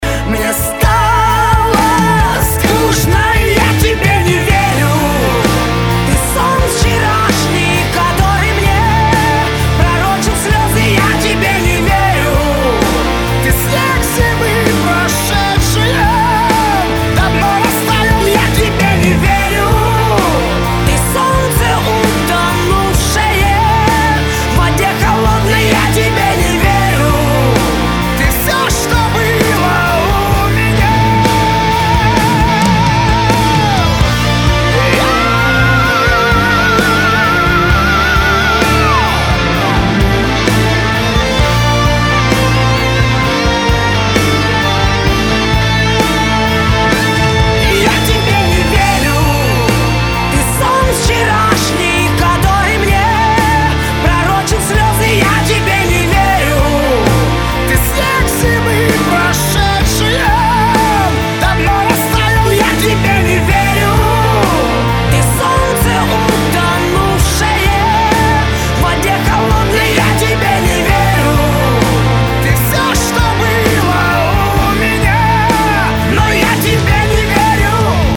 Главная страница>>Скачать mp3>>Рок рингтоны